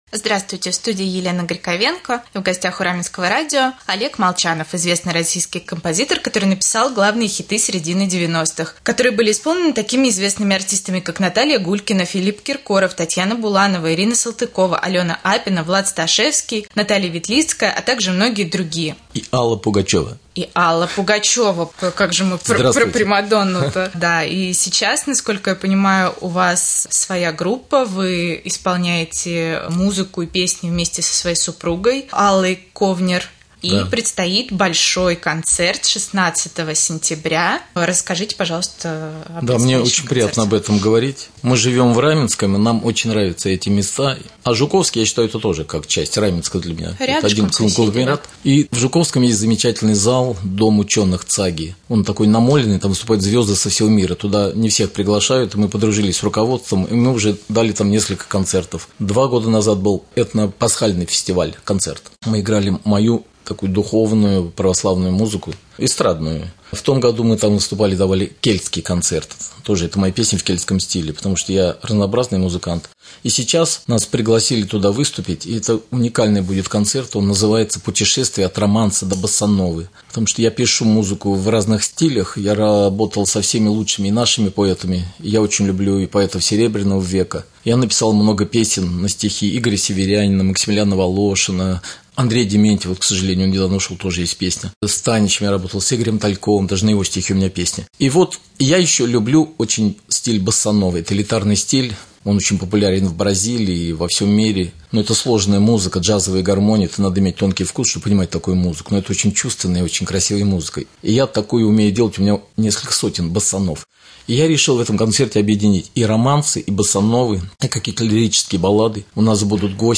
Олег Молчанов, известный российский композитор, рассказал о своем творчестве